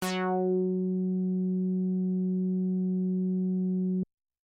标签： midivelocity48 F4 midinote66 YamahaCS30L synthesizer singlenote multisample
声道立体声